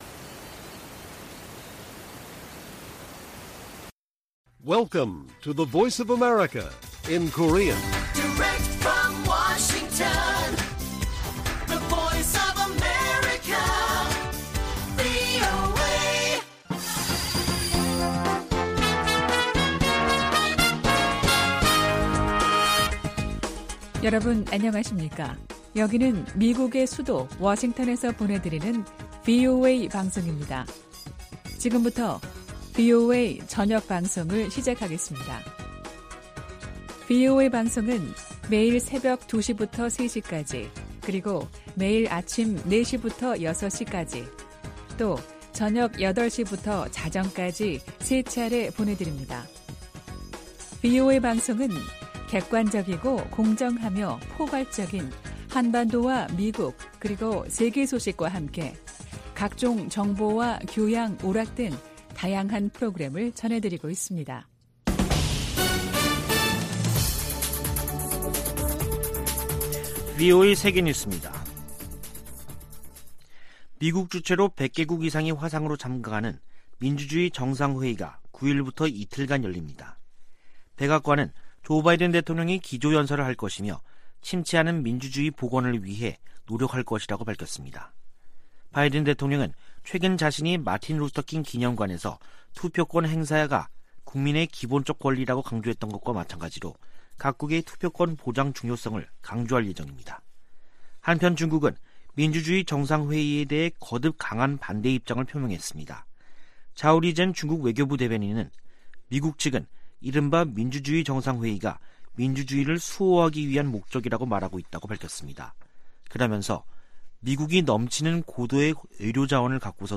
VOA 한국어 간판 뉴스 프로그램 '뉴스 투데이', 2021년 12월 9일 1부 방송입니다. 미국 공화당 하원의원 35명이 한국전쟁 종전선언을 반대하는 서한을 백악관에 보냈습니다. 한국 정부는 북한을 향해 종전선언 호응을 촉구하며 돌파구를 찾고 있지만 별다른 반응을 이끌어내지 못하고 있습니다. 미 상·하원 군사위가 합의한 2022회계연도 국방수권법안(NDAA) 최종안에는 '웜비어 법안' 등 한반도 안건이 대부분 제외됐습니다.